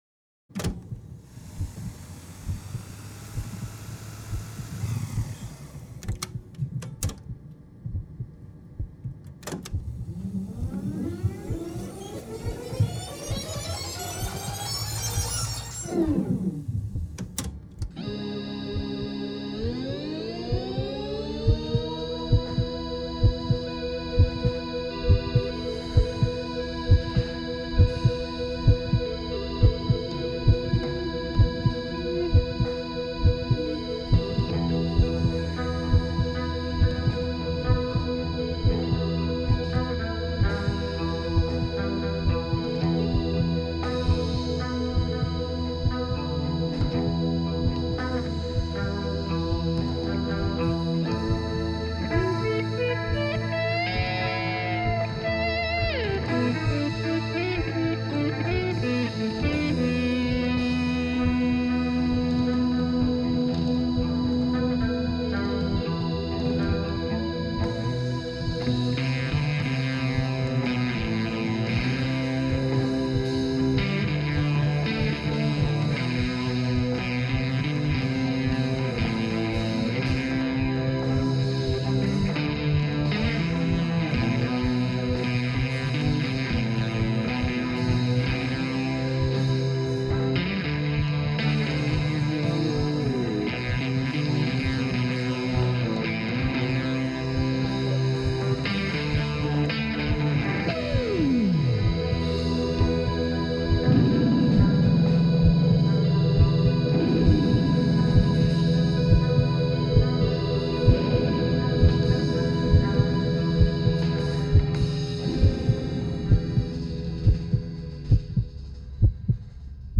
rock band of Piacenza.
instrumental